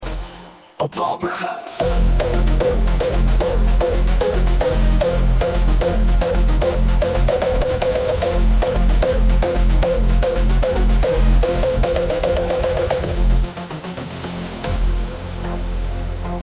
op walburgen goaltune Meme Sound Effect
op walburgen goaltune.mp3